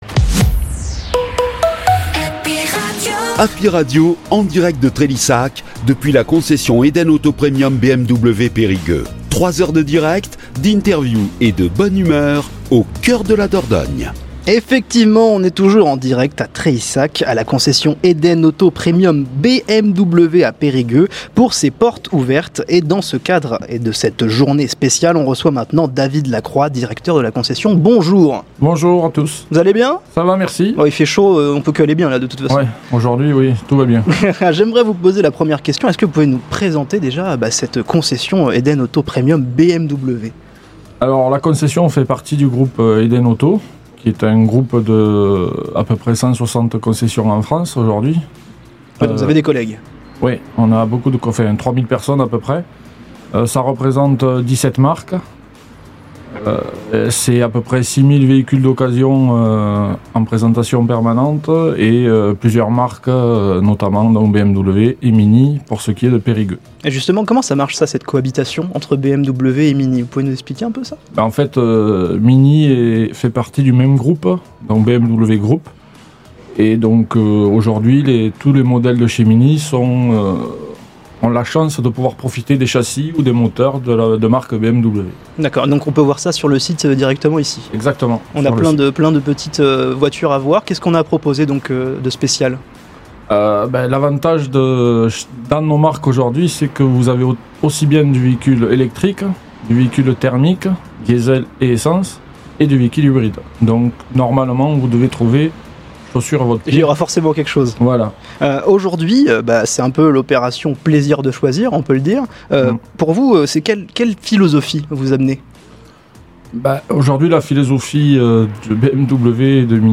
Les interviews Happy Radio